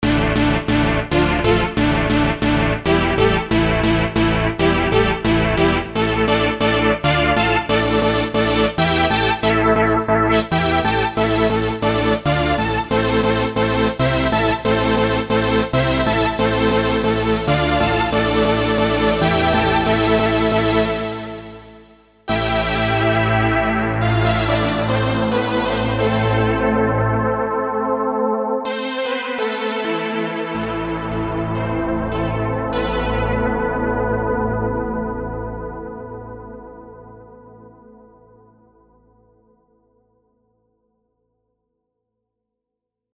Trance песочница (крутим суперпилы на всём подряд)